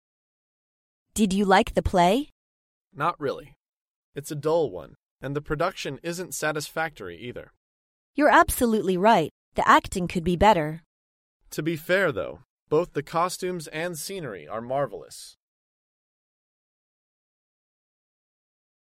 在线英语听力室高频英语口语对话 第459期:看话剧的听力文件下载,《高频英语口语对话》栏目包含了日常生活中经常使用的英语情景对话，是学习英语口语，能够帮助英语爱好者在听英语对话的过程中，积累英语口语习语知识，提高英语听说水平，并通过栏目中的中英文字幕和音频MP3文件，提高英语语感。